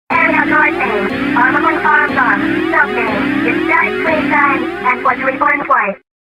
shmup ship computer infodumping at you about bosses